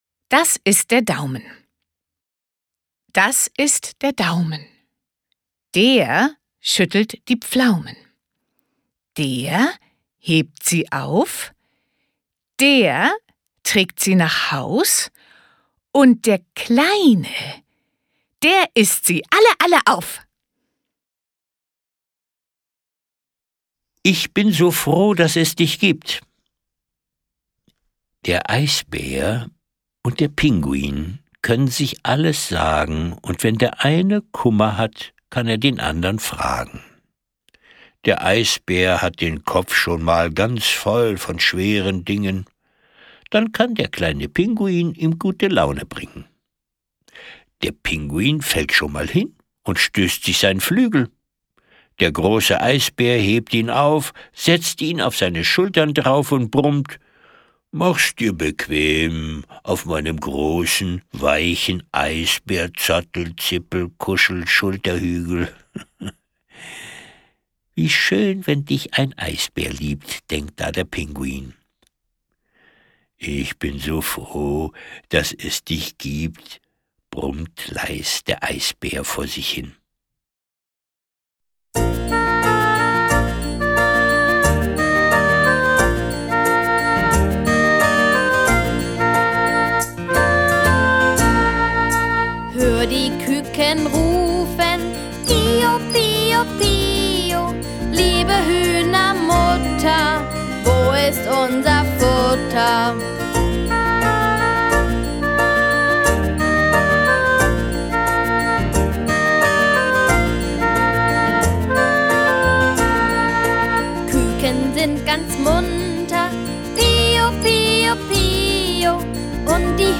Lieder, Verse und Geschichten